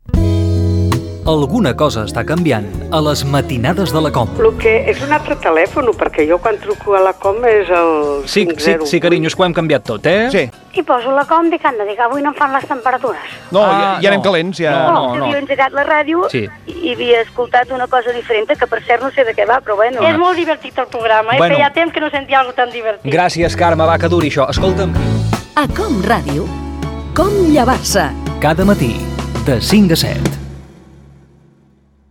Promoció del programa
FM